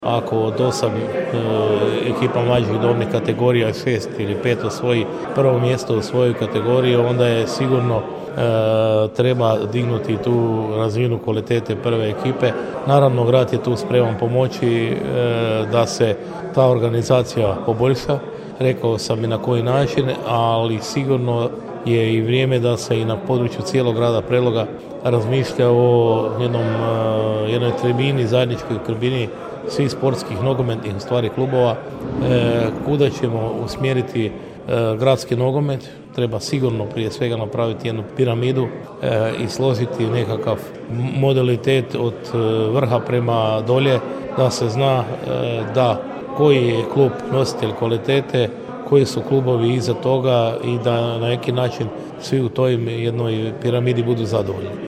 NK Mladost Komet, redovna godišnja skupština kluba, 10.2.2023. / Poduzetnički centar Prelog